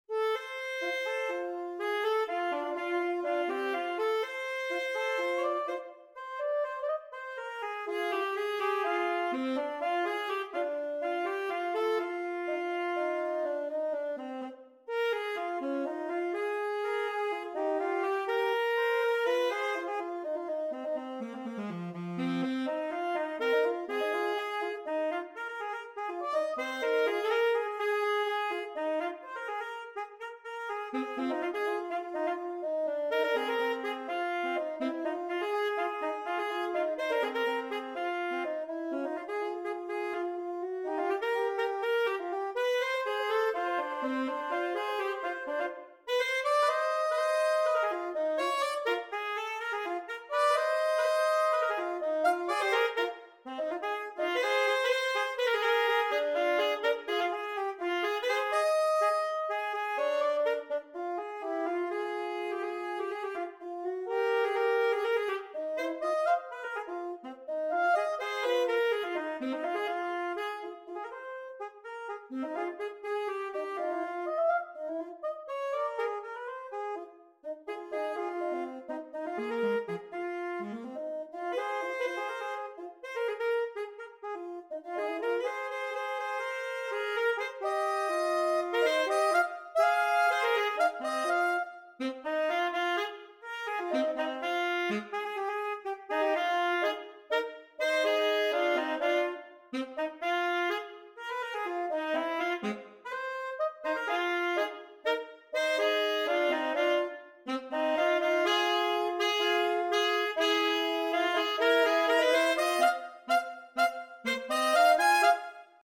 Gattung: Für 2 Altsaxophone
Besetzung: Instrumentalnoten für Saxophon